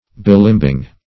Meaning of bilimbing. bilimbing synonyms, pronunciation, spelling and more from Free Dictionary.
Search Result for " bilimbing" : The Collaborative International Dictionary of English v.0.48: Bilimbi \Bi*lim"bi\, Bilimbing \Bi*lim"bing\, n. [Malay.]